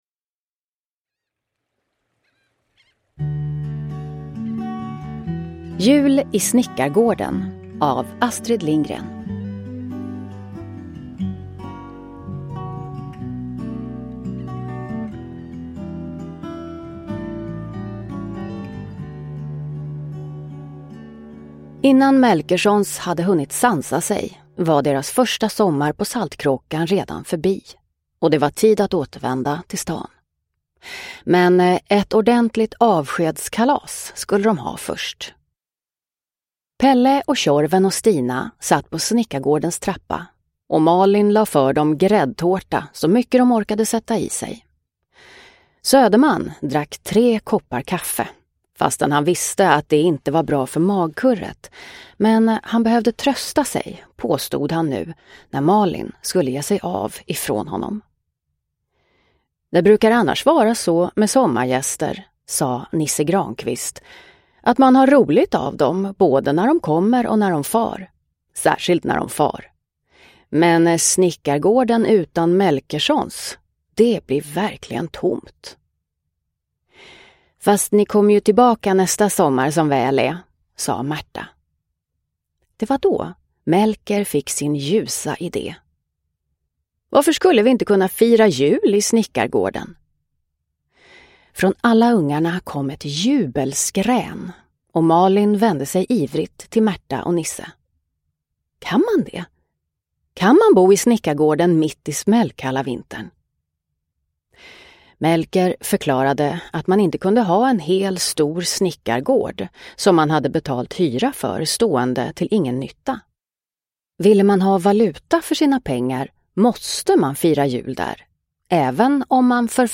Jul i Snickargården – Ljudbok – Laddas ner